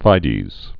(fīdēz, fīdz)